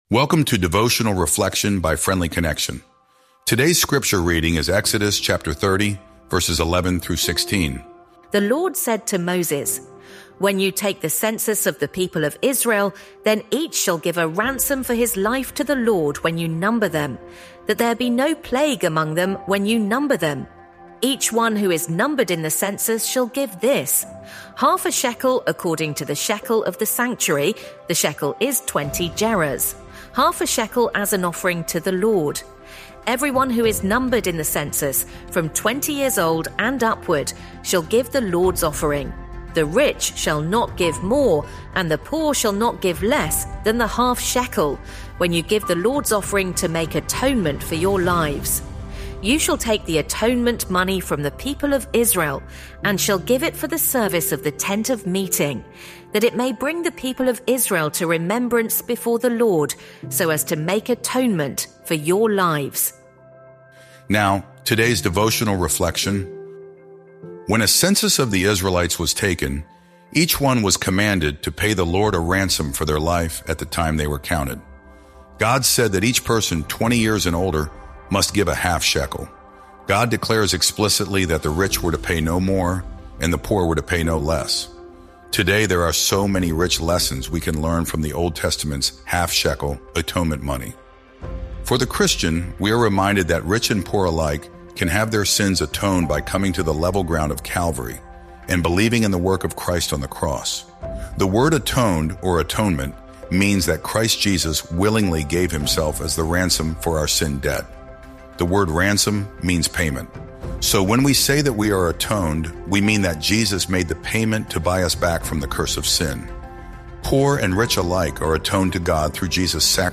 Sermons | Honey Creek New Providence Friends Church